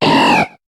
Cri de Barloche dans Pokémon HOME.